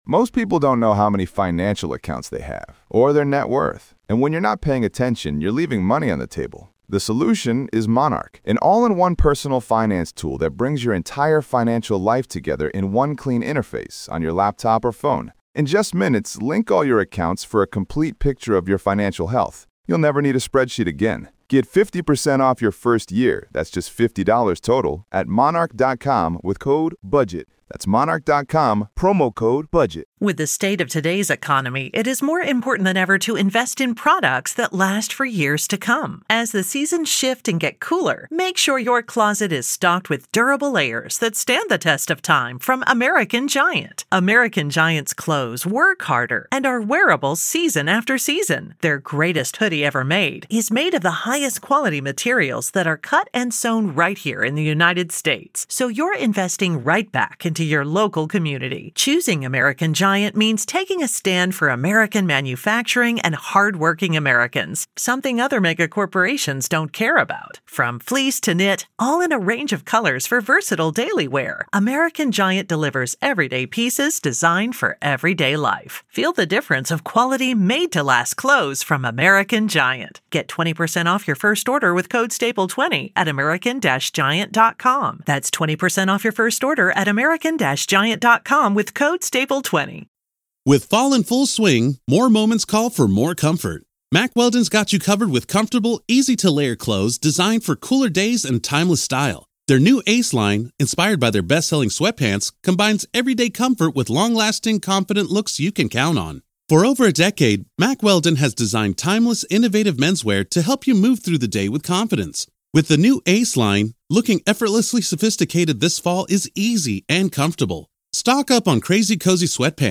True Crime